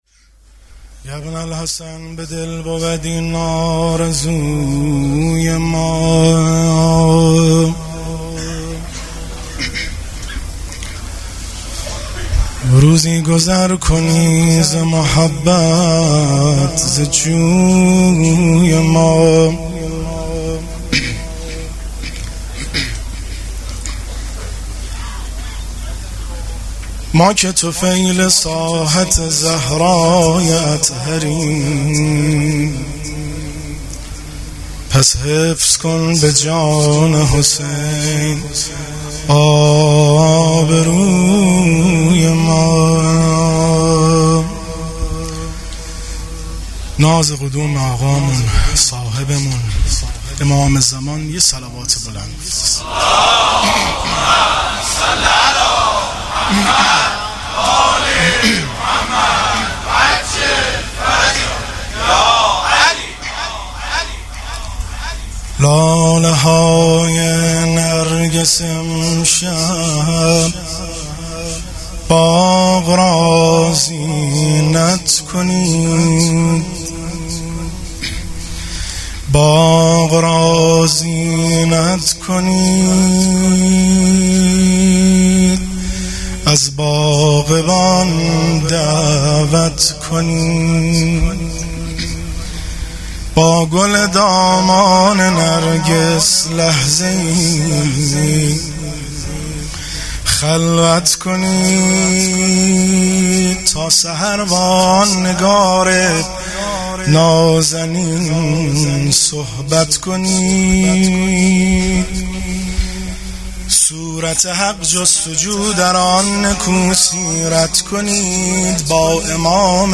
مدح خوانی – میلاد حضرت مهدی (عج الله تعالی فرجه شریف) 1398